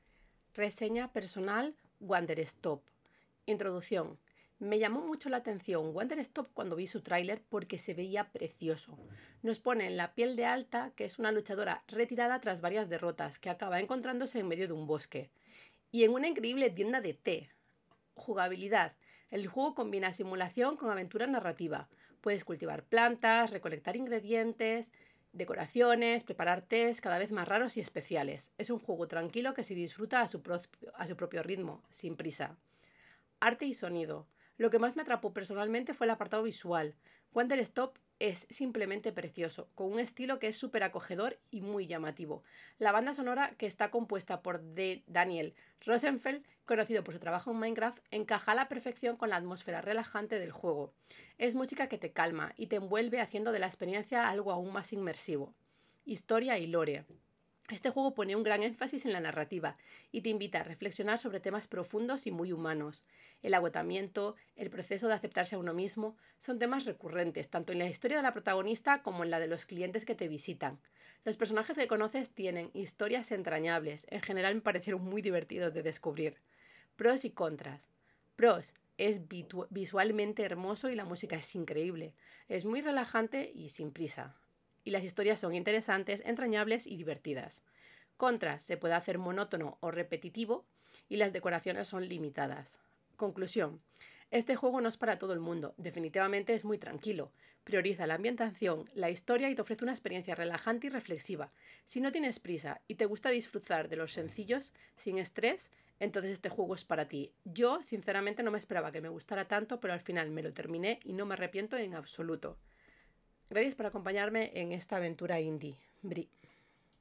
Reseña personal en audio: